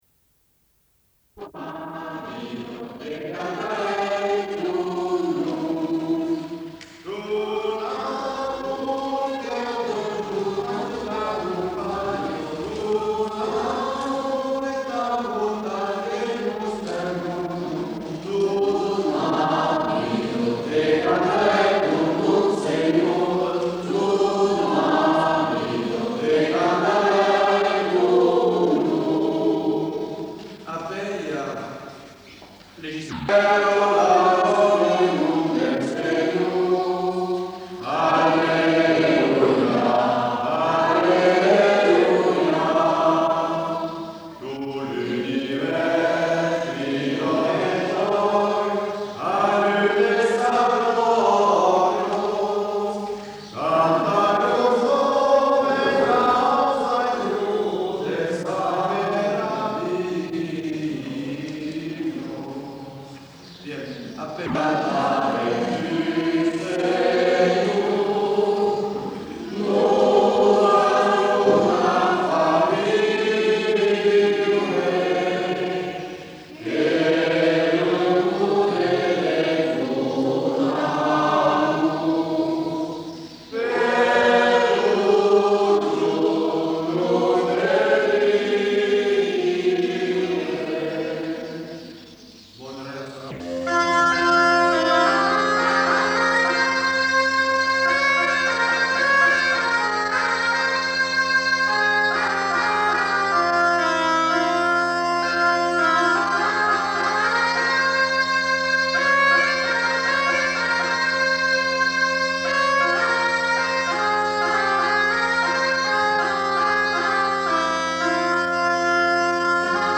Durée/Pagination : 22min 07s Type de son : stéréo Qualité technique : mauvais Résumé : Enregistrements de chants en occitan, latin et français ainsi que trois morceaux instrumentaux interprétés à la bodega dont un noël lors de la messe donnée en l'église Saint-Exupere à Blagnac le 12 juin 1982. Domaines : musique-chant
Notes consultables : Beaucoup de coupures de début et de fin de morceaux.